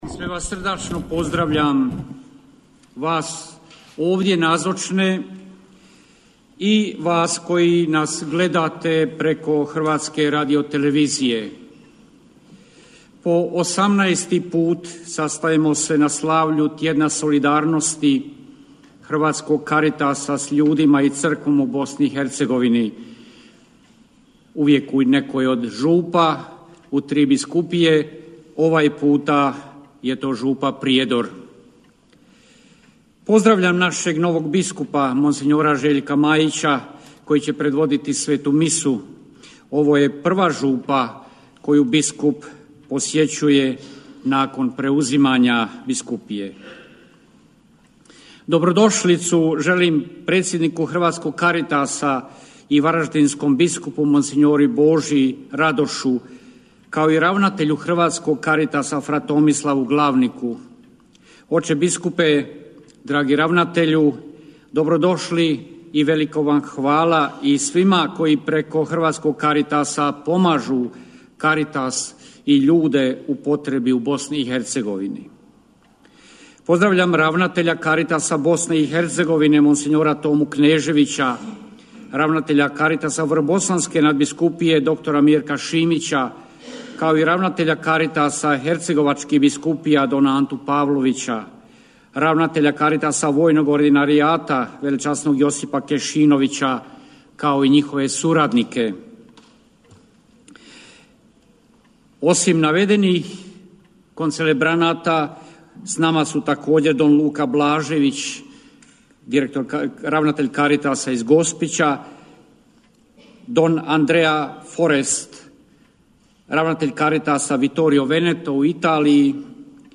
Euharistijsko slavlje u okviru Tjedna solidarnosti, koji se ove godine odvija pod motom „Solidarnost u deset riječi!“, izravno je prenosila Hrvatska Radiotelevizija na svom prvom kanalu, a izravni prijenos preuzimala je i RTV Herceg-Bosna.